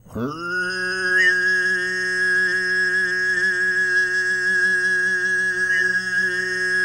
TUV1 DRONE11.wav